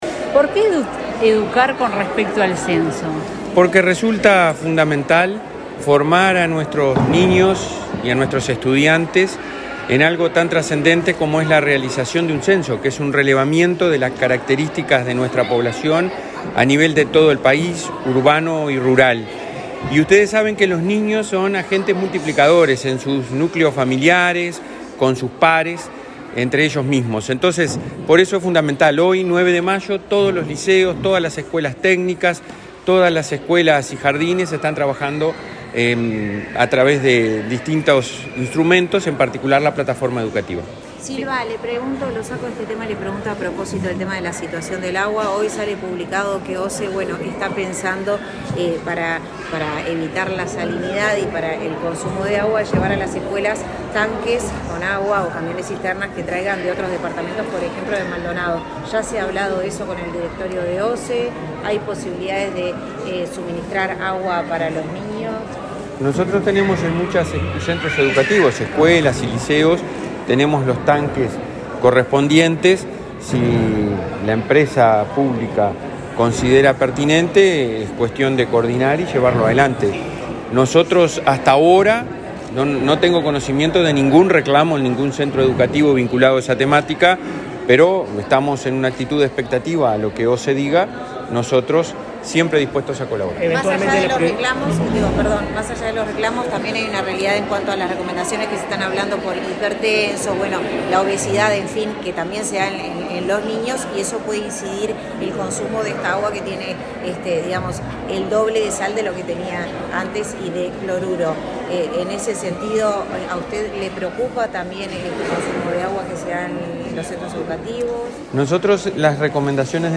Declaraciones del presidente del Codicen, Robert Silva
El presidente del Codicen, Robert Silva, dialogó con la prensa antes de participar del lanzamiento del Día Nacional del Censo 2023 en la educación,